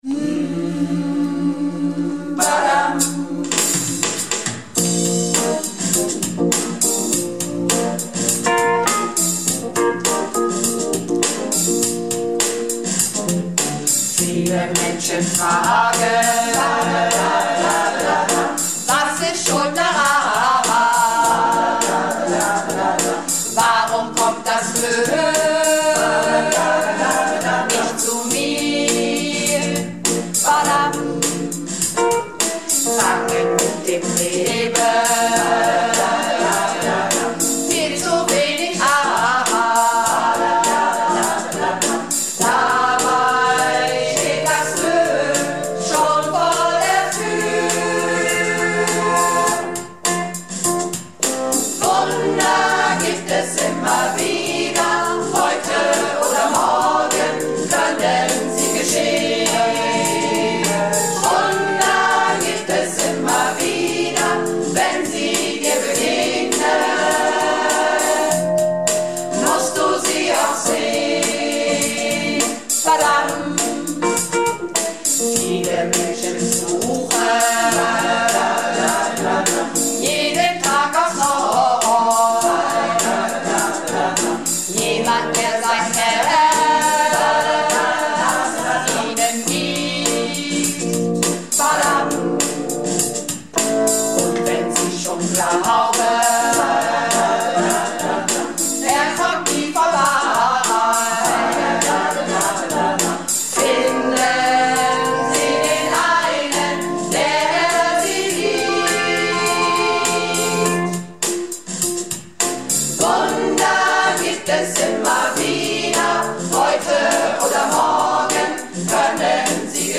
Malle Diven - Probe am 29.01.20